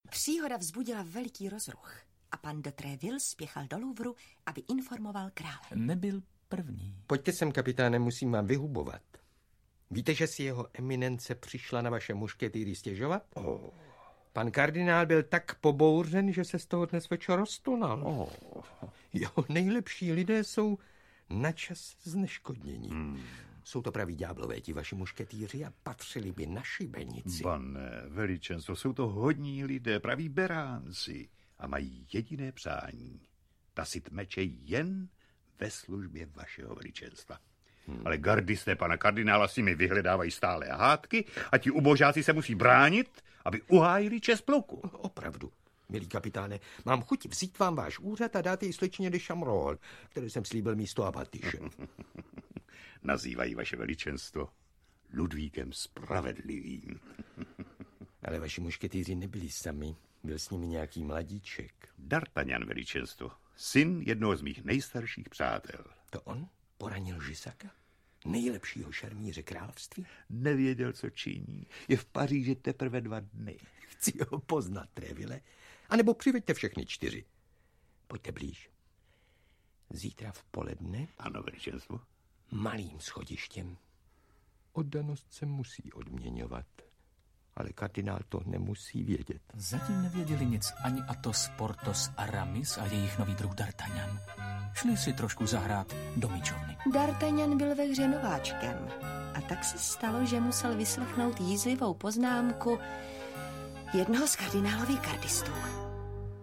Ukázka z knihy
My Vám nabízíme velkolepou zvukovou dramatizaci, v níž uslyšíte celou plejádu vynikajicích herců: Viktora Preisse, Miroslava Moravce, Jiřího Sováka, Růženu Merunkovou, Blanku Bohdanovou, Václava Postráneckého, Rudolfa Hrušínského a další